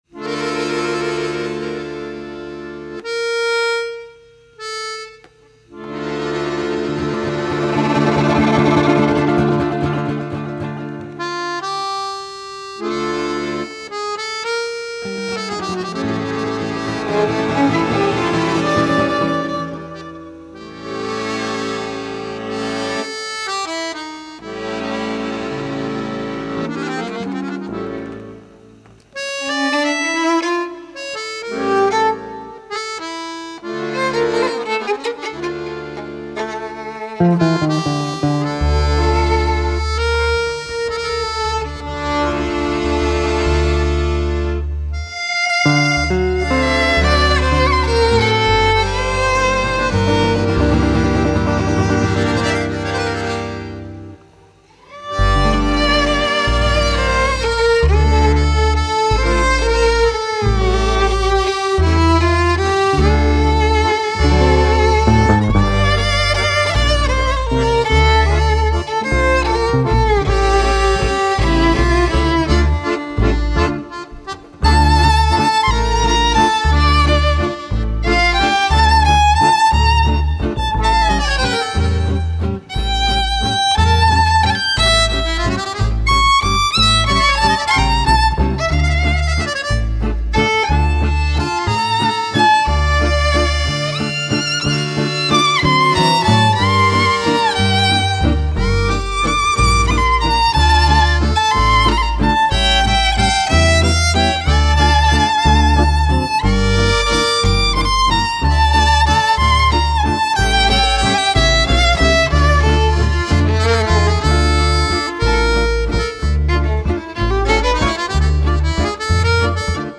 Guitar/D'rbukka
Violin/Viola
Accordian
Double Bass
Drums